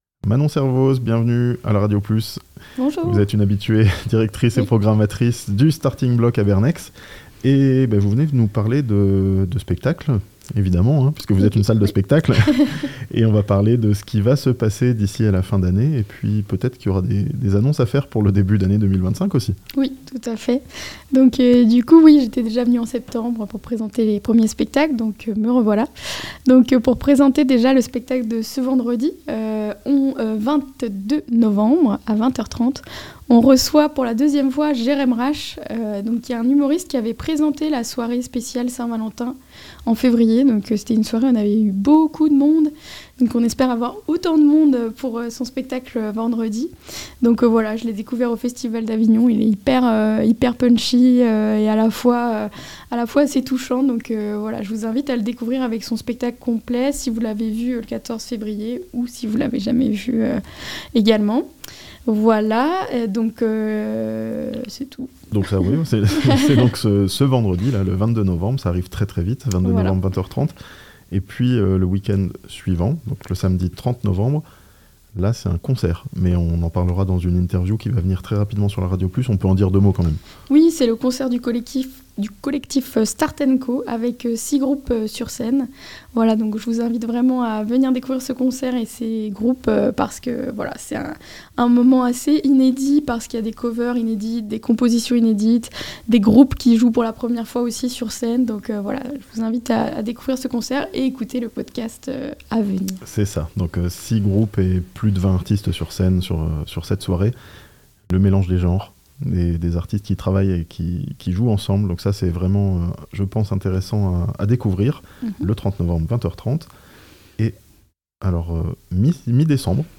A Bernex dans le Chablais, une programmation variée pour le Starting Block en cette fin d'année (interview)